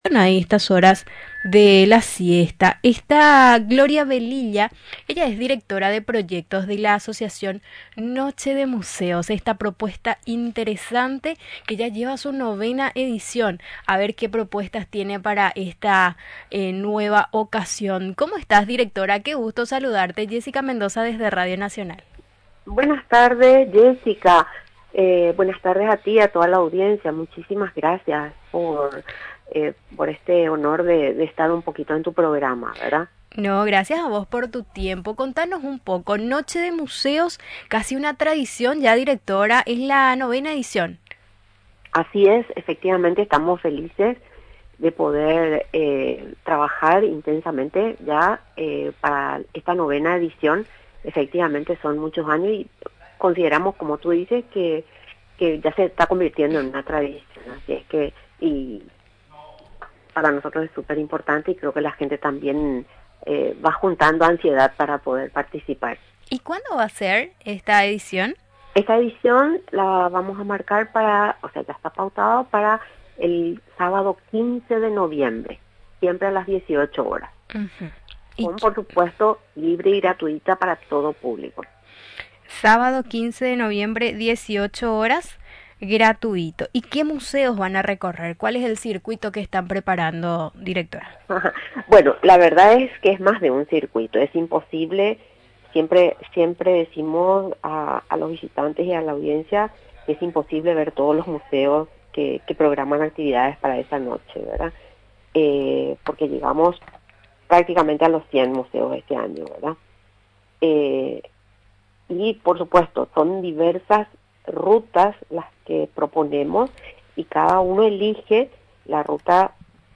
Durante la entrevista en Radio Nacional del Paraguay, explicó los detalles y la agenda que se tiene prevista para la mencionada jornada.